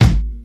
neptuneshardasskick2.wav